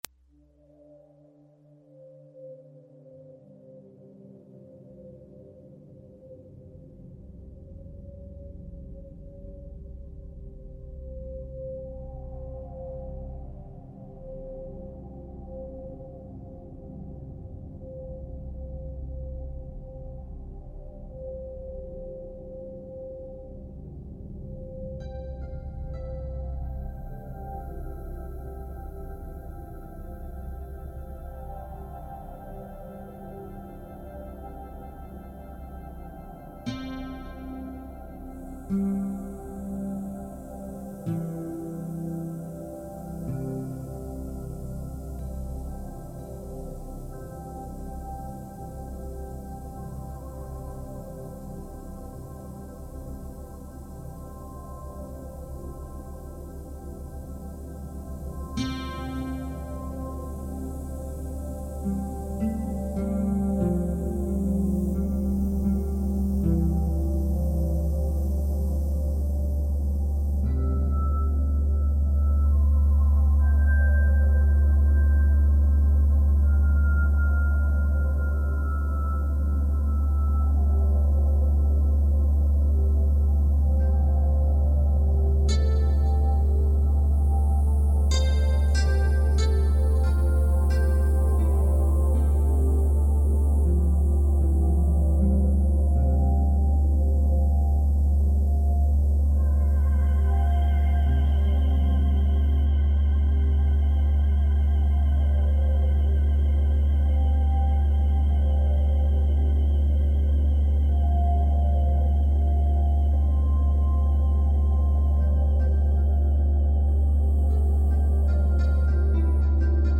File under: Ambient / Avantgarde